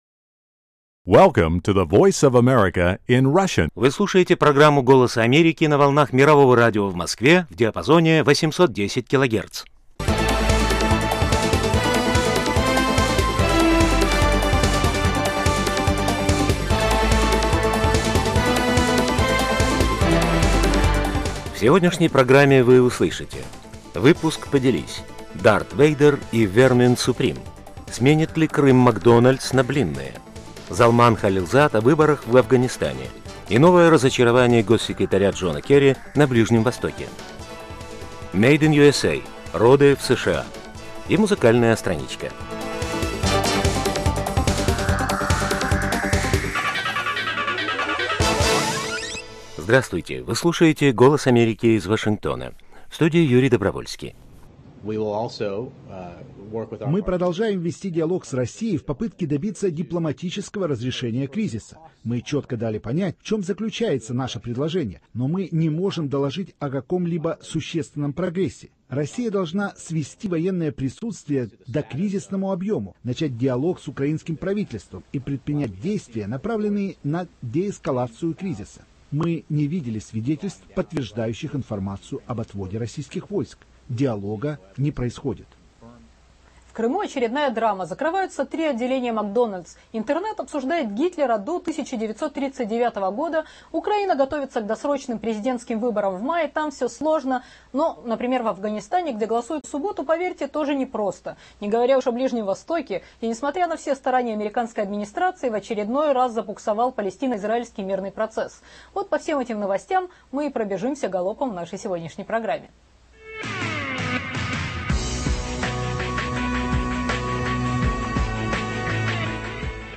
Обсуждение самых горячих новостей и противоречивых мнений ведущих экспертов в 17-00 по московскому времени на радиочастоте 810 КГц